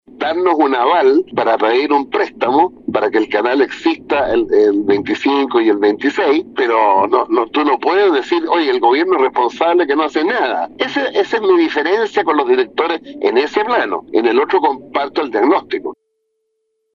En conversación con La Radio, Vidal explicó que el préstamo con aval del Estado por $24 mil millones —de los cuales ya se utilizaron $12 mil— permitirá mantener la operación de TVN durante los próximos dos años.